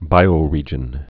(bīō-rējən)